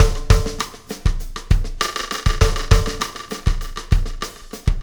Pulsar Beat 10.wav